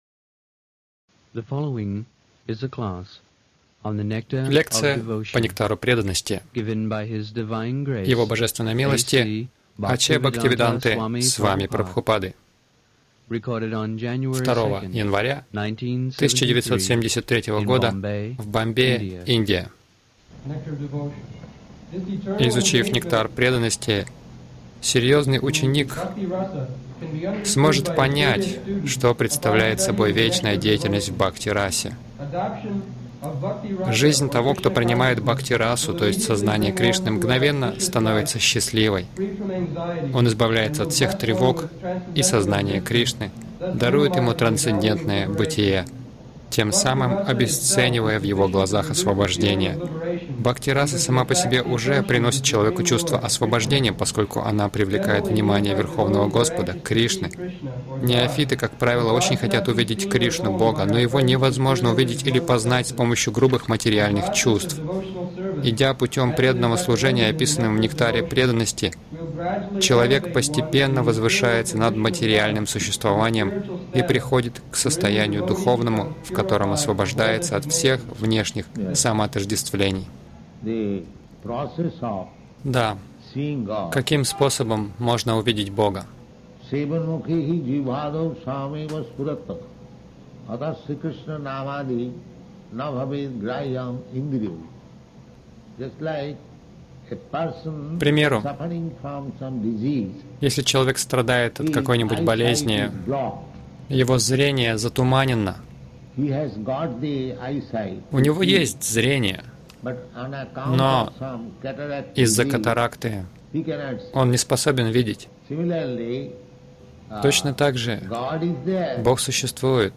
Милость Прабхупады Аудиолекции и книги 02.01.1973 Нектар Преданности | Бомбей Нектар Преданноcти Предисловие стр. 11 — Не любовь, а вожделение Загрузка...